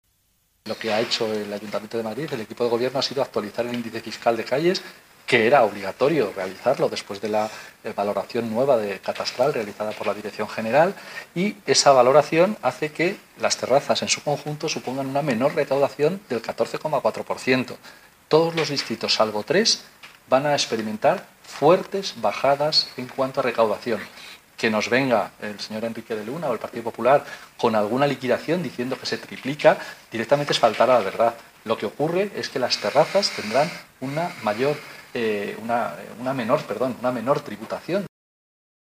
Nueva ventana:Sánchez Mato explica en qué ha consistido este reajuste de tasas de terrazas y por qué tendrán una menor tributación